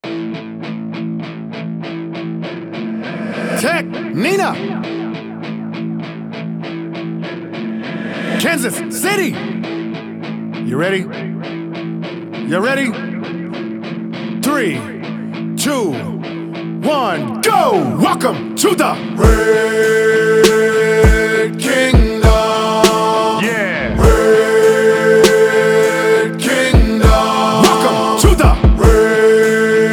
• Rap